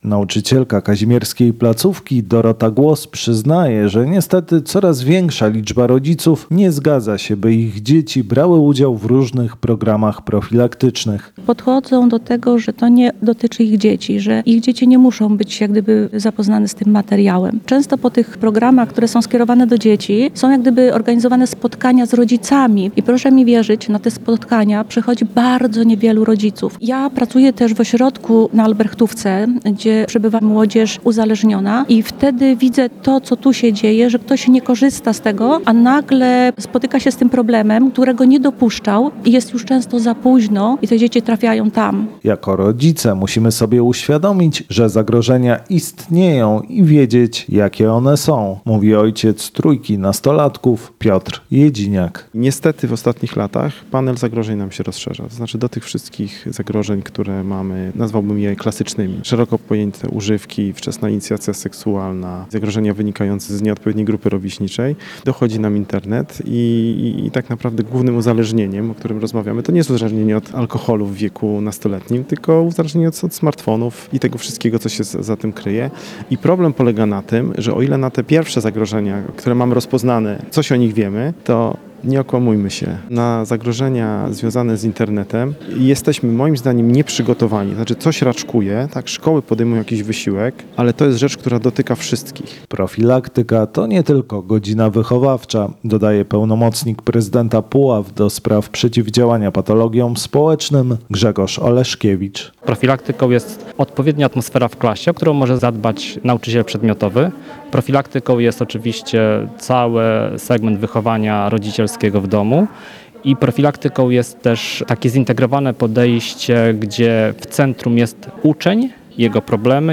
Na ogromną rolę profilaktyki szkolnej zwracali uwagę specjaliści podczas Forum Profilaktycznego organizowanego przez puławski urząd miasta. Dziewiąta edycja tego wydarzenia rozpoczęła się debatą w Gminnym Zespole Szkół w Kazimierzu Dolnym.